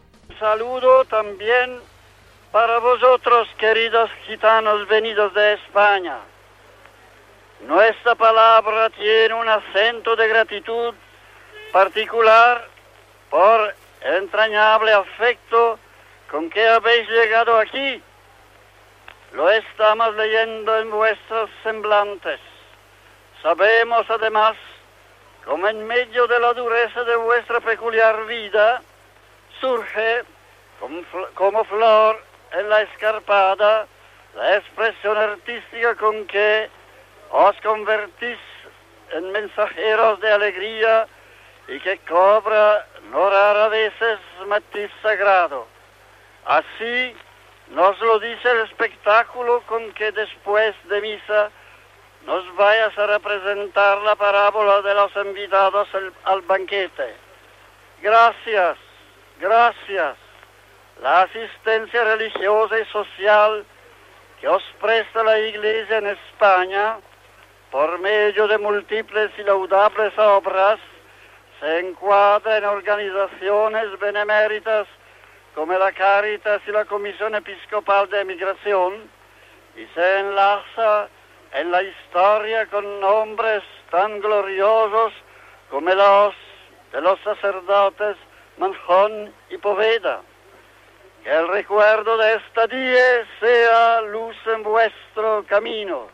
El Sant Pare Pau VI saluda als gitanos espanyols en la missa celebrada a Pomezia (Itàlia) amb la comunitat gitana de tot el món
Religió